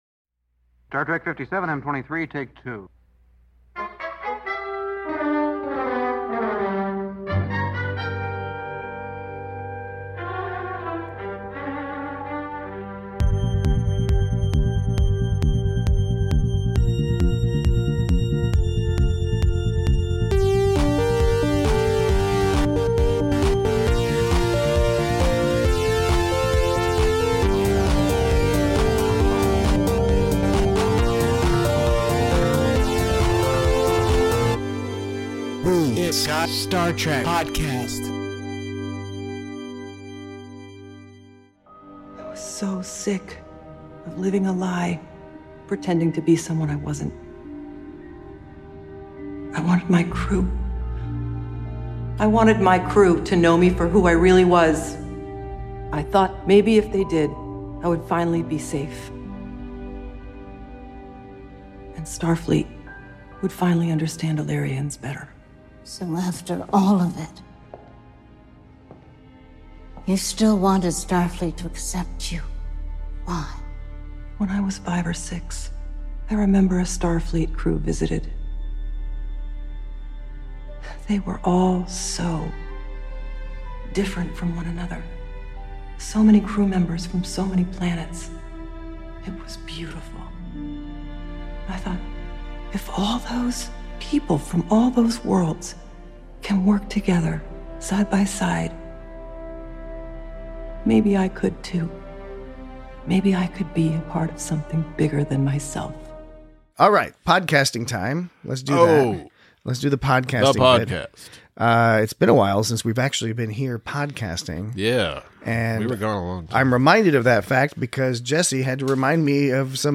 Lieutenant Commander Chin-Riley and her old buddy Neera kick prejudice's ass. Join your harried hosts as they provide an embriefened catchup conversation about the timelessness of talky episodes, parallels to other classic court case-based Trekisodes, and, of course, Bergmann's ecogeographical rule regarding surface-area-to-volume ratios in high latitude endotherms.